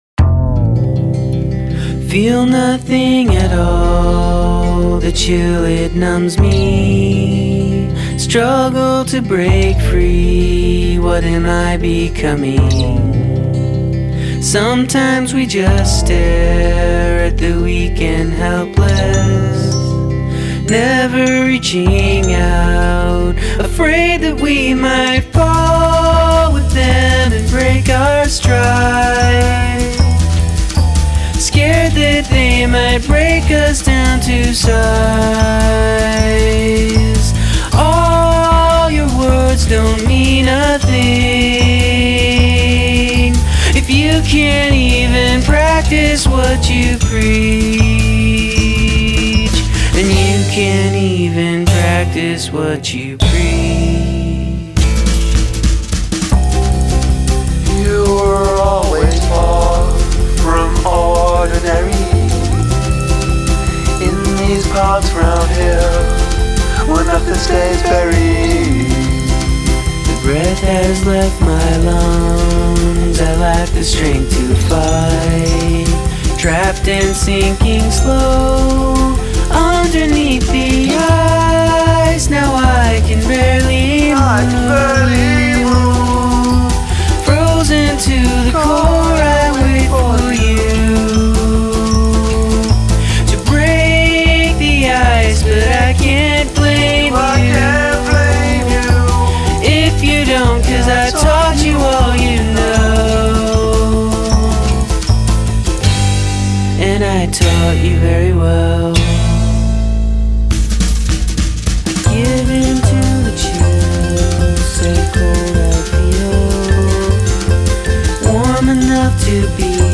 with deft guitar and percussion work.